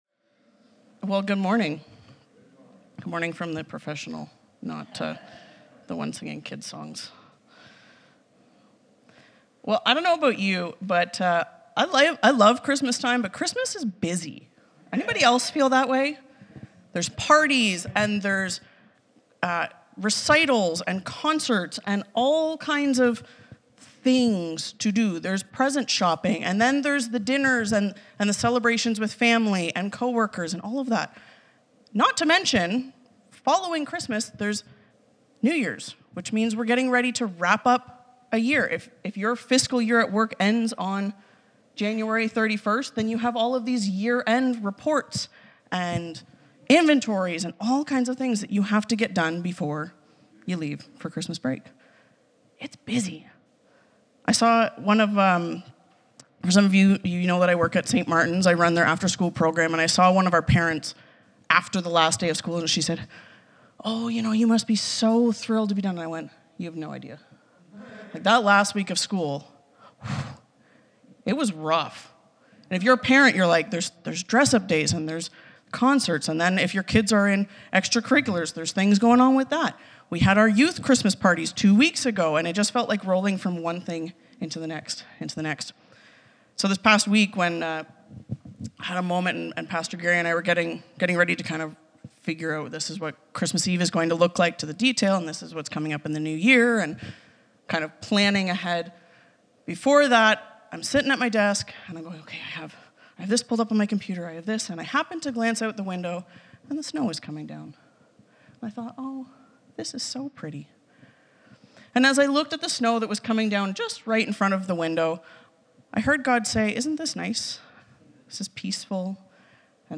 The sermon emphasizes the power of memories and how they influence our relationship with God.